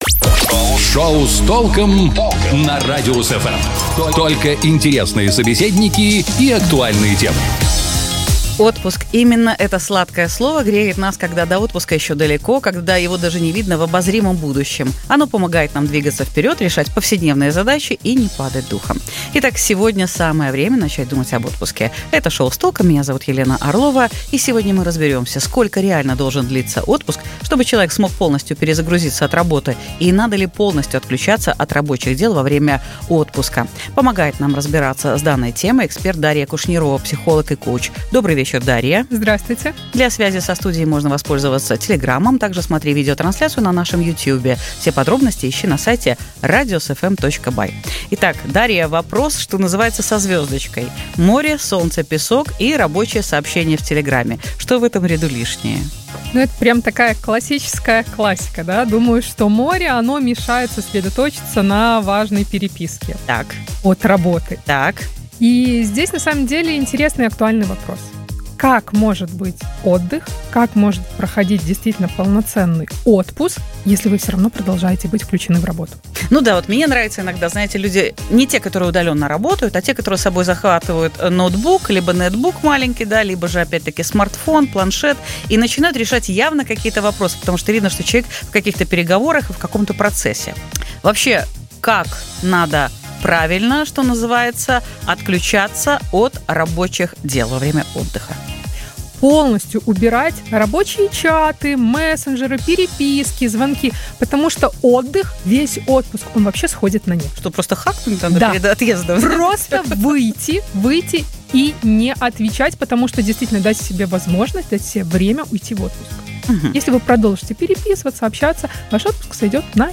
Психолог о правильном отдыхе